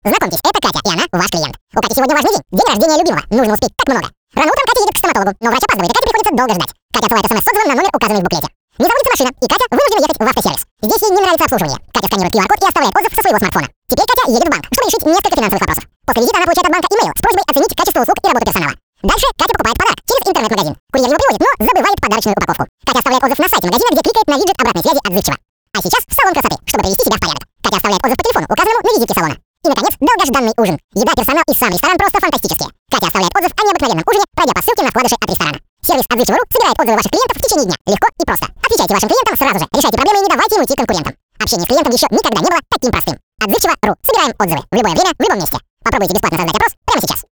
Озвучивание текста для сервиса "Отзывчиво.Ру"